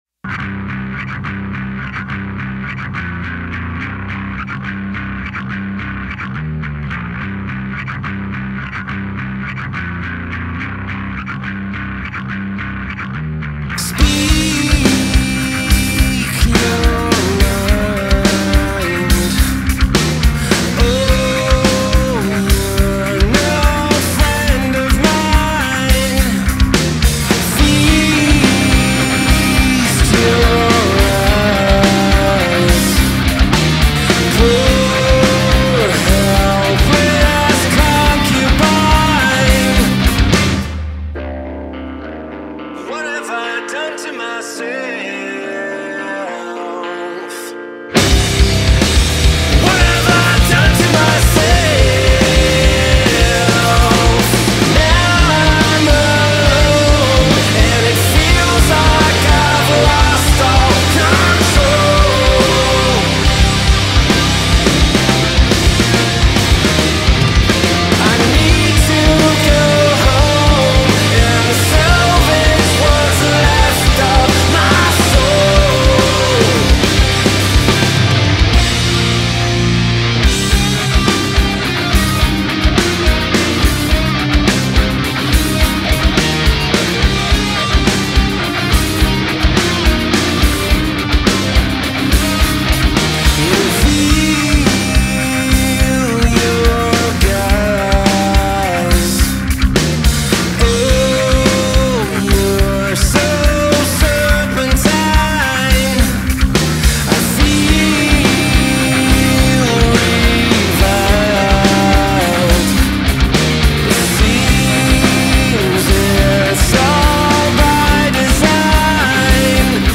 The energy is relentless, but it never feels empty.